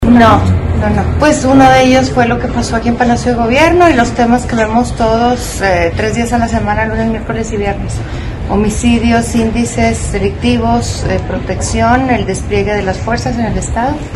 Durante la reunión de la mesa de seguridad, la gobernadora María Eugenia Campos Galván, dijo desconocer a cuánto hacienden los daños que se ocasionaron el 8 de marzo con motivo del Día Internacional de la Mujer.